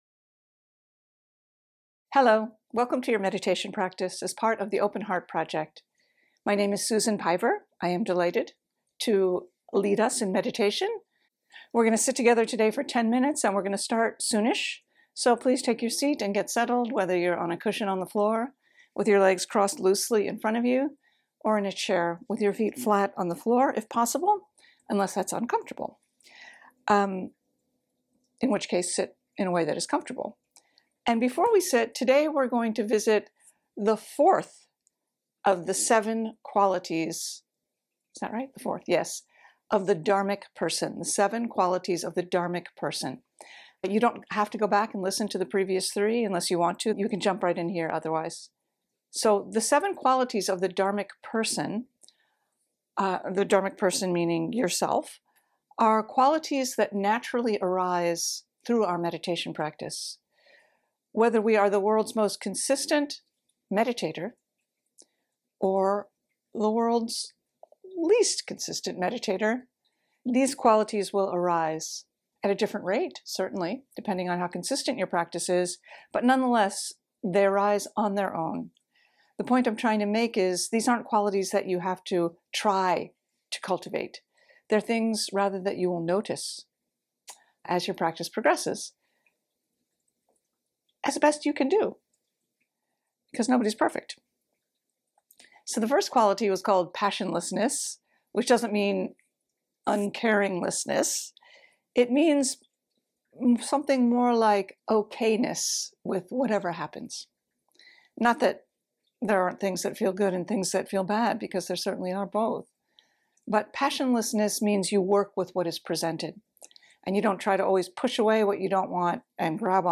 Meditation practice begins at 10:28.